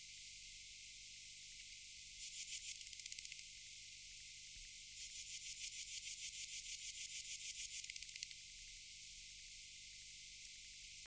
Data resource Xeno-canto - Orthoptera sounds from around the world